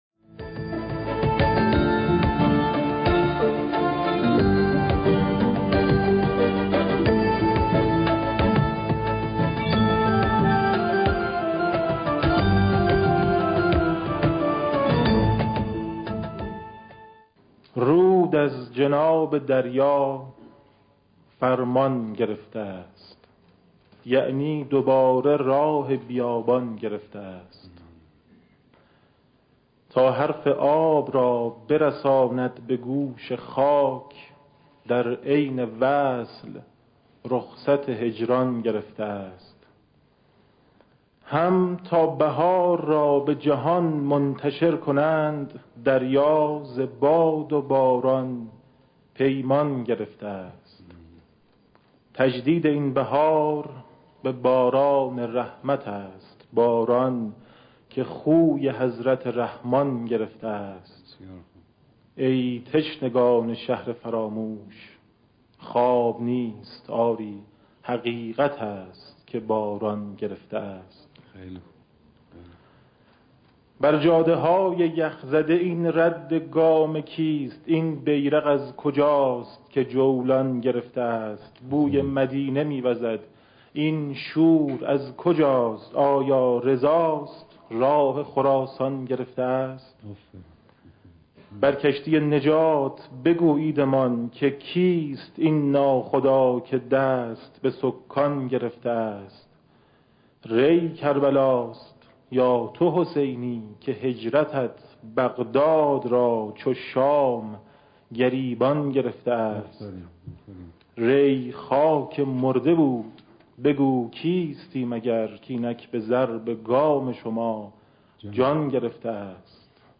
گلچین مداحی های میلاد حضرت عبدالعظیم(ع)